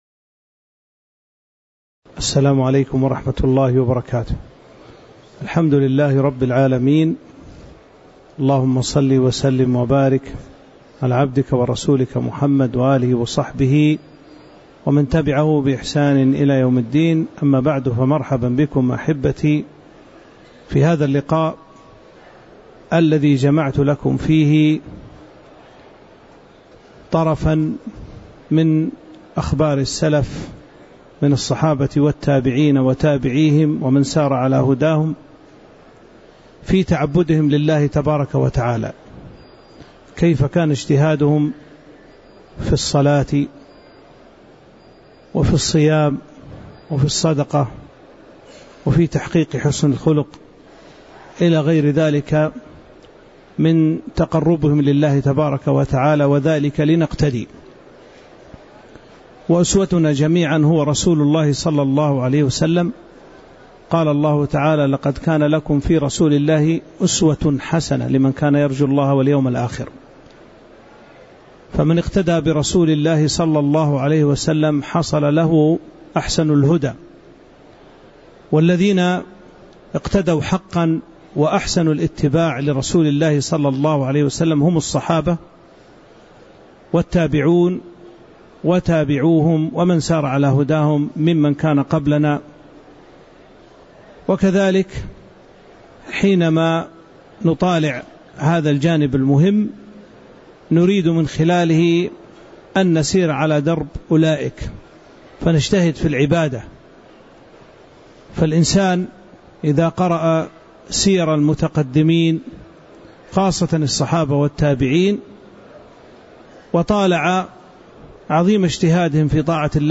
تاريخ النشر ٢٦ ذو الحجة ١٤٤٥ هـ المكان: المسجد النبوي الشيخ